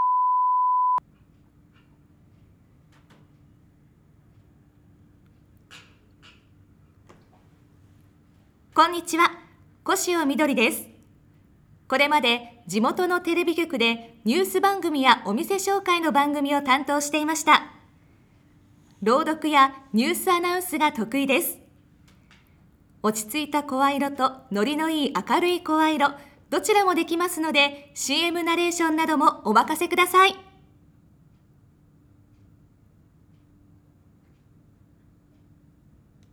自己紹介